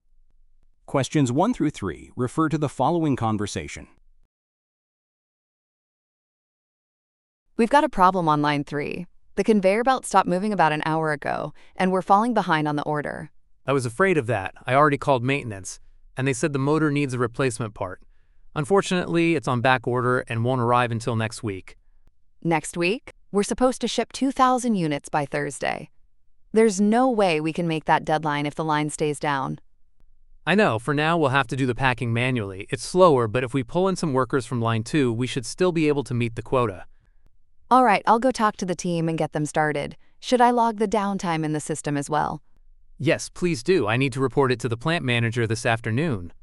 ※TOEICは、アメリカ/イギリス/オーストラリア/カナダ発音で出ます。